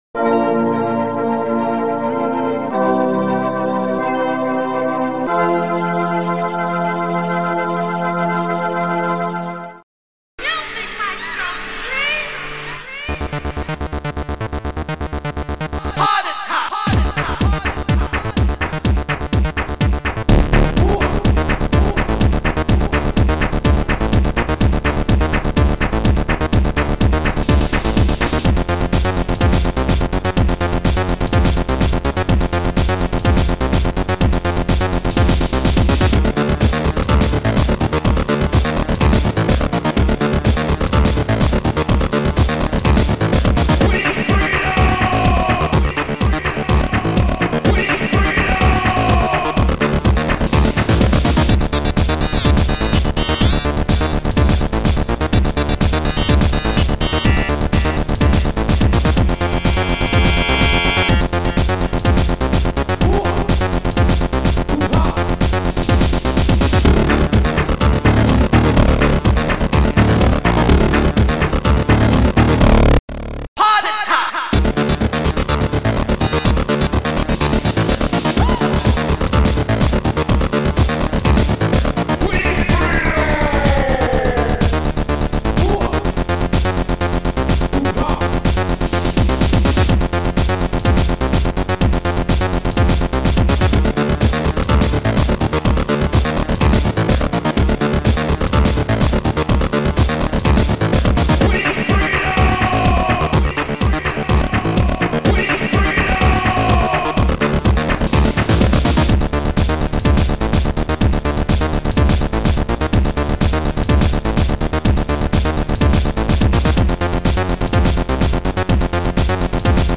Sound Format: Noisetracker/Protracker
Sound Style: Techno